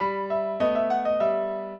piano
minuet9-9.wav